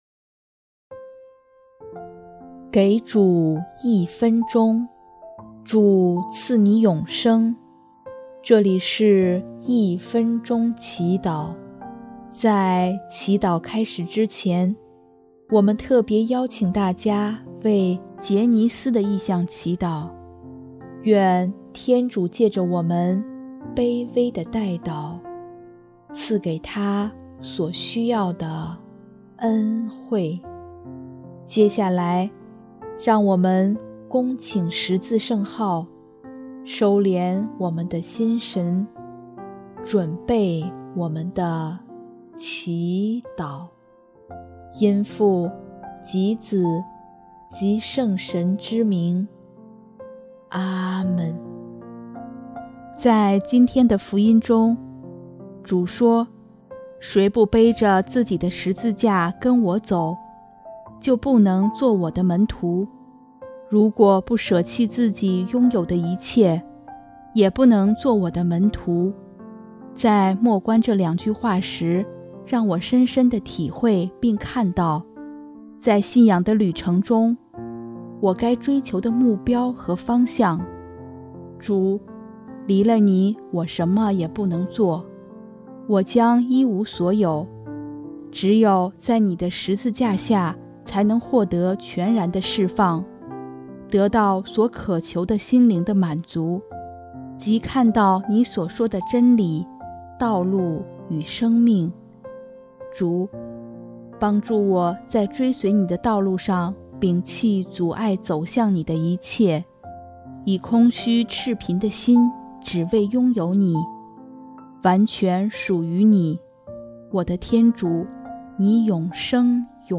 【一分钟祈祷】|9月4日 主，我愿完全属于祢